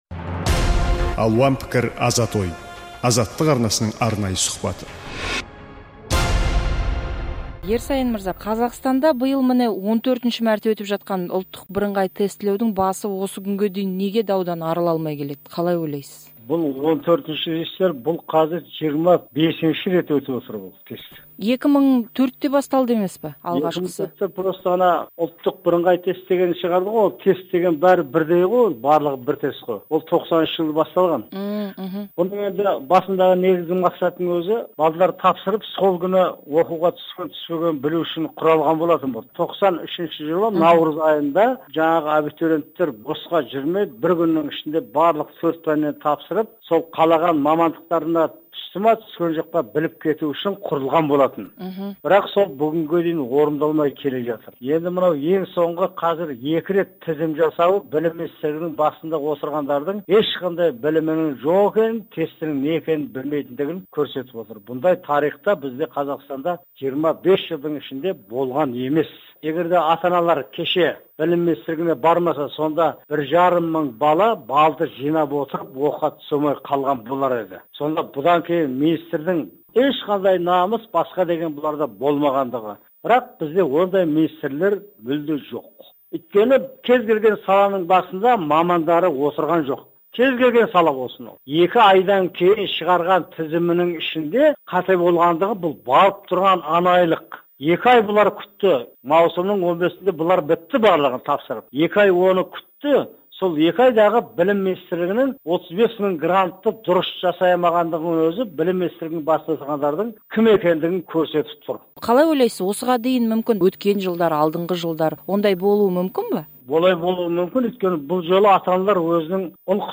Қазақстанда биыл 14-мәрте өткен ҰБТ-дан кейін қорытынды тізімнен дау туып, білім және ғылым министрлігі түлектерге бөлінген ЖОО гранттарының екінші мәрте жаңа тізімін жасауға мәжбүр болды. Азаттыққа сұқбат берген «Ұрпақ тағдыры